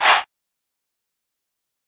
SouthSide Chant (38).wav